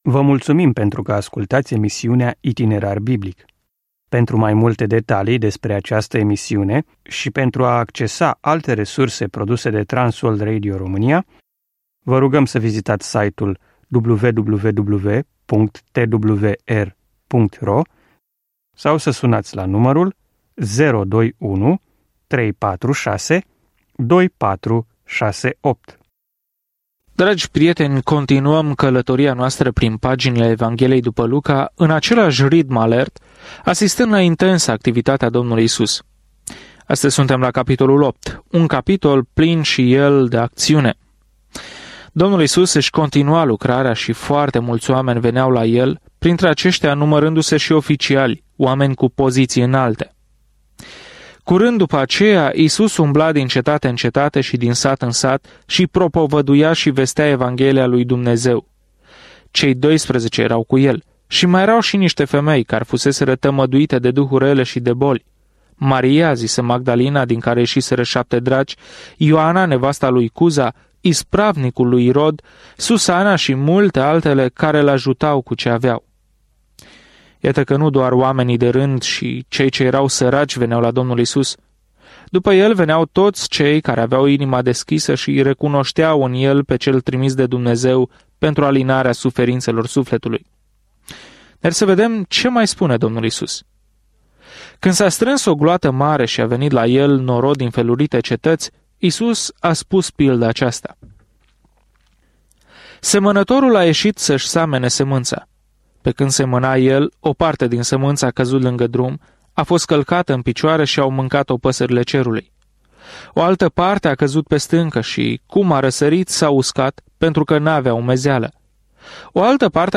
Călătoriți zilnic prin Luca în timp ce ascultați studiul audio și citiți versete selectate din Cuvântul lui Dumnezeu.